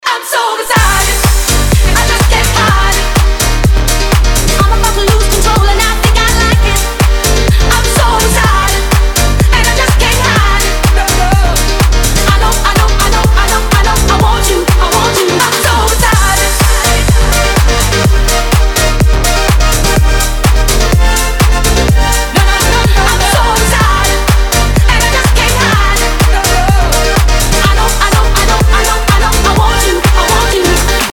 • Качество: 320, Stereo
громкие
быстрые